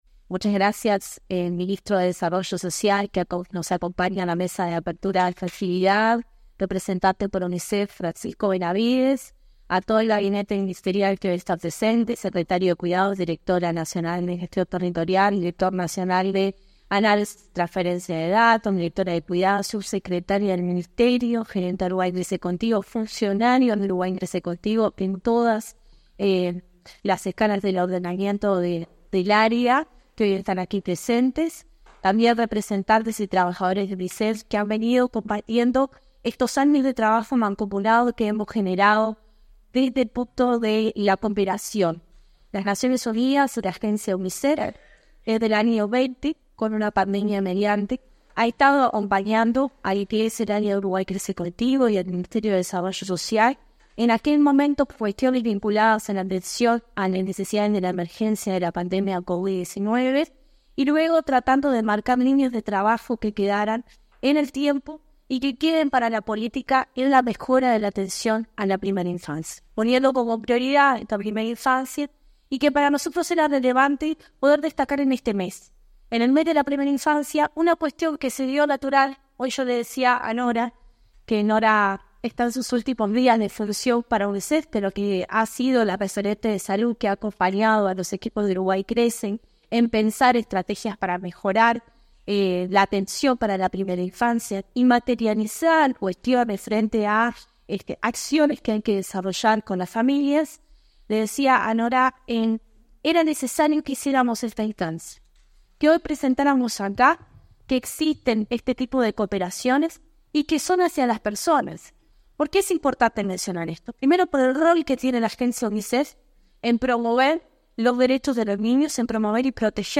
Palabras de autoridades en acto del Mides